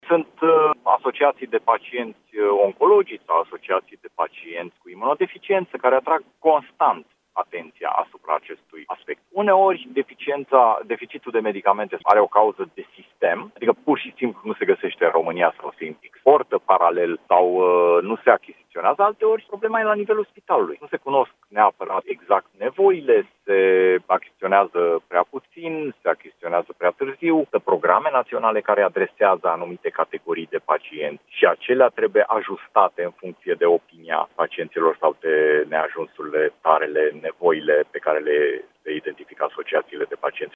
Adrian Wiener, deputat USR și co-inițiator al proiectului: „Sunt asociații de pacienți oncologici sau asociații de pacienți cu imunodeficiență care atrag constant atenția asupra acestui aspect”